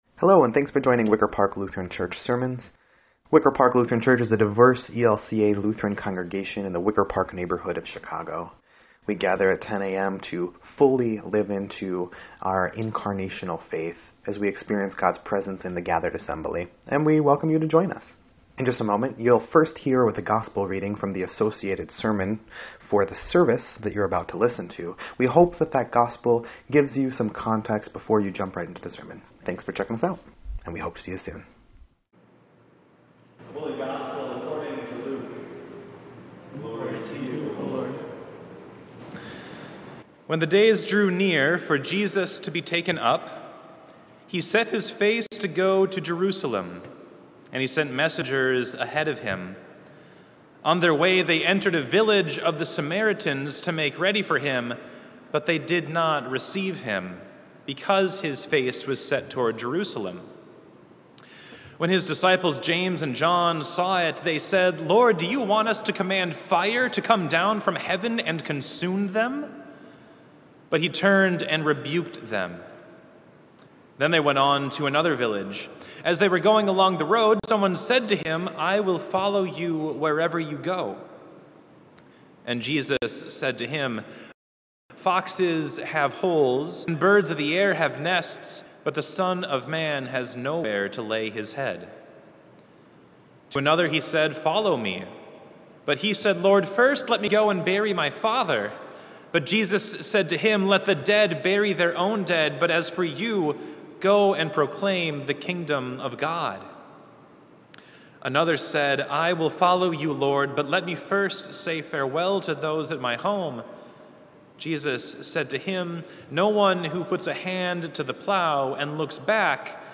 6.26.22-Sermon_EDIT.mp3